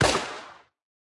Media:Colt_baby_atk_1.wavMedia:Colt_base_atk_1.wav 攻击音效 atk 初级和经典及以上形态攻击音效
Colt_baby_atk_1.wav